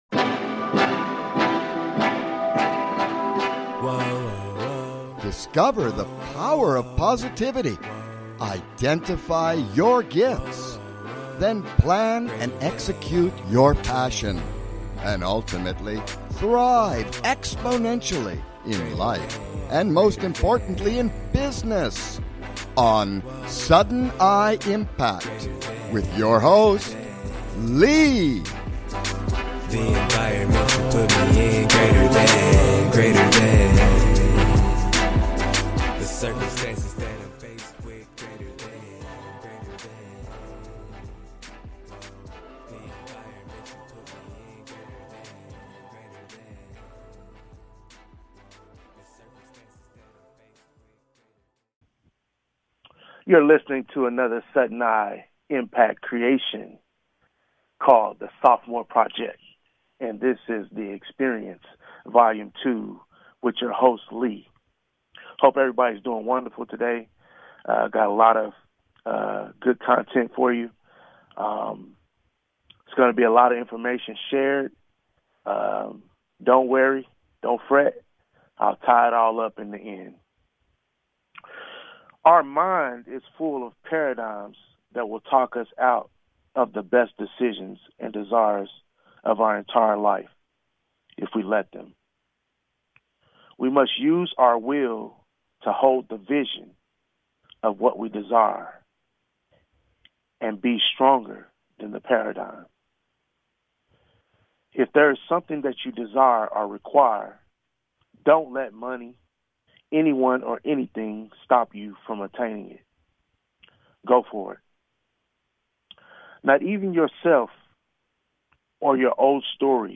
Talk Show Episode, Audio Podcast, Sudden I Impact and The Sophomore Project, The Experience Volume II on , show guests , about The Sophomore Project,The Experience Volume II, categorized as Health & Lifestyle,Kids & Family,Psychology,Emotional Health and Freedom,Mental Health,Personal Development,Self Help,Inspirational,Motivational